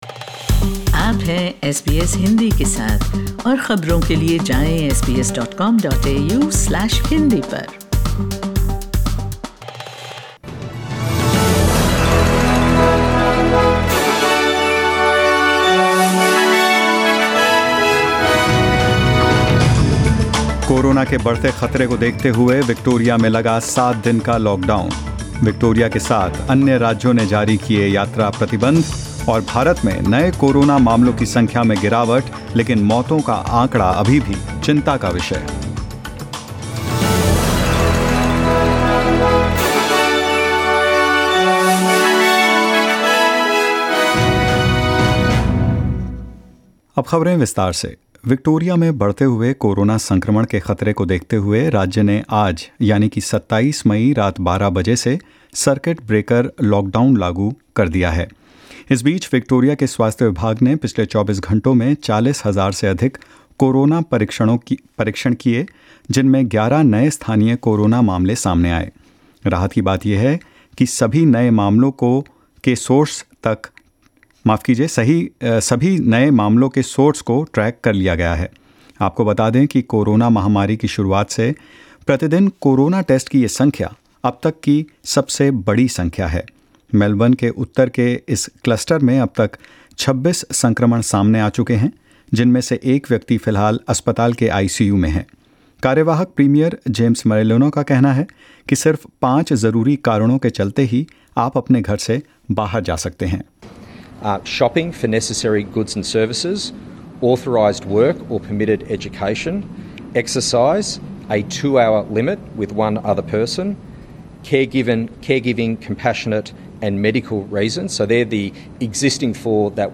In this latest SBS Hindi News bulletin of Australia and India: Victoria goes into a week-long lockdown after Covid-19 cases rise in Melbourne; India witnesses a dip in coronavirus cases but death rate continues to worry authorities and more. 26/05/2021